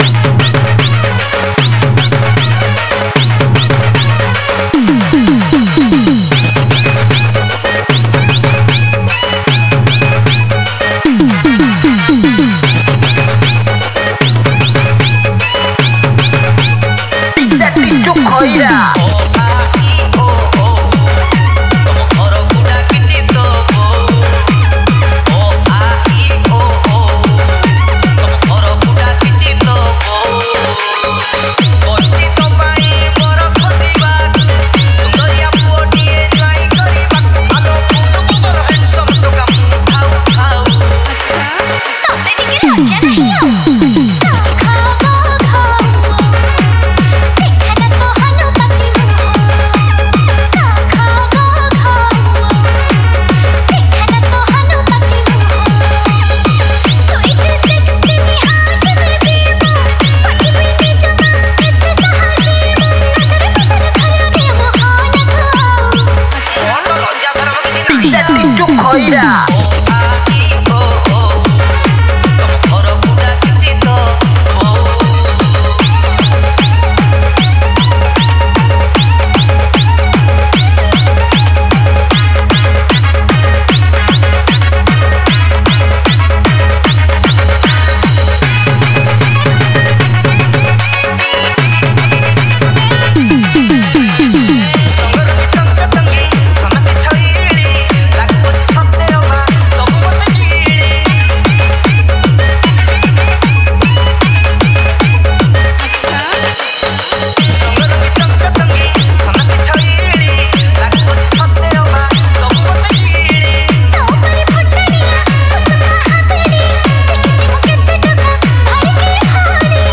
New Odia Dj Song 2024